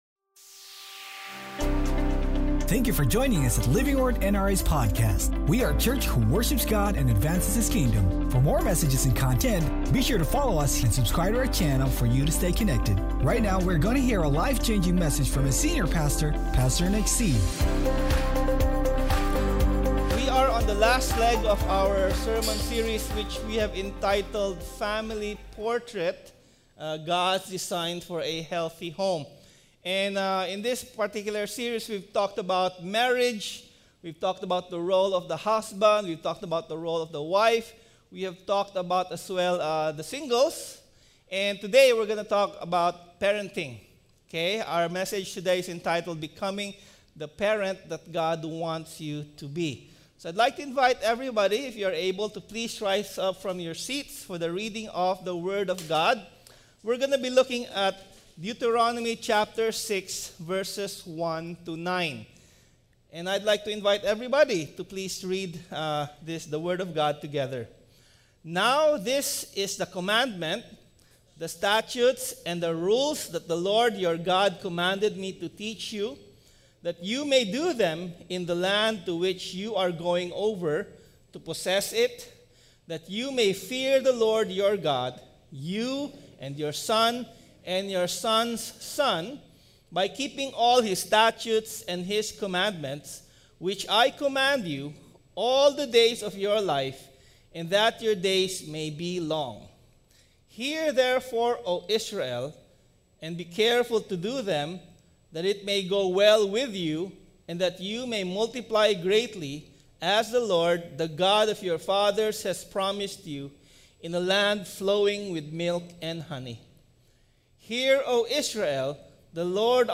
If we don’t teach our children to follow Christ, the world will teach them not to. Sermon Title: BECOMING THE PARENT GOD WANTS YOU TO BE